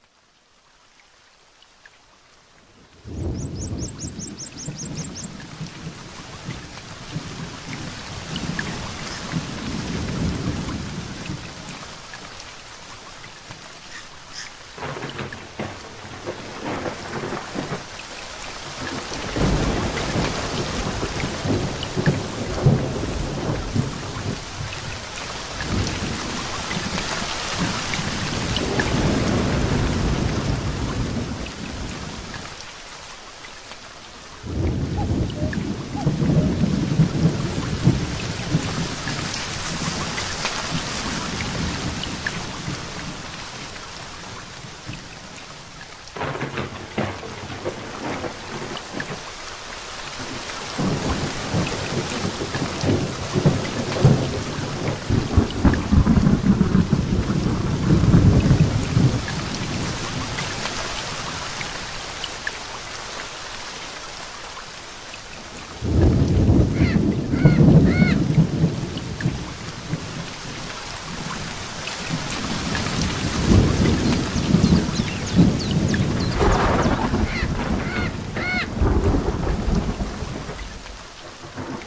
Nature sounds are recorded & designed to help people sleep, allowing you to relax and enjoy the sounds of nature while you rest or focus with no adverts or interruptions.
Perfect for their masking effects, they are also helpful for people suffering with tinnitus.
Tropical-Storm-Sample.mp3